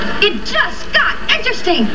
"It just got interesting!"- I thought it was a spiffy way Monica from Friends said it.